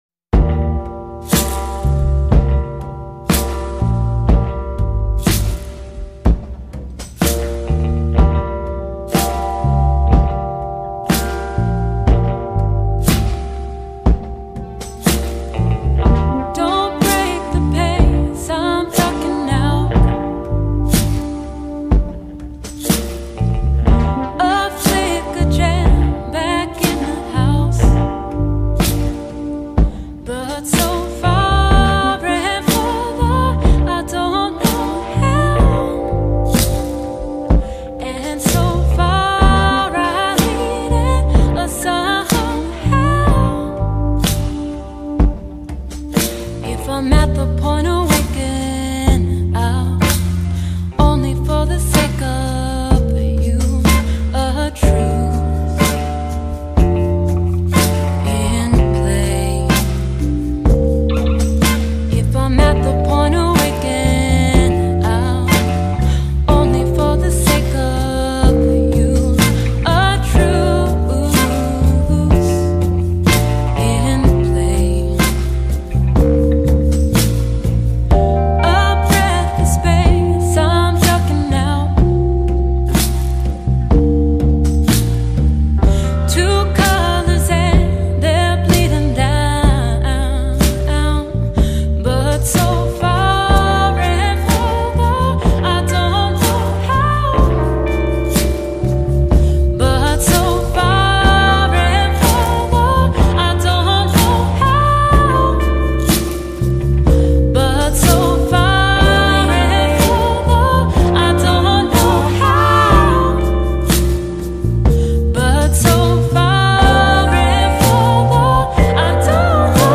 Le septet new yorkais
aux tendances RnB